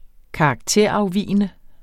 Udtale [ -ɑwˌviˀənə ]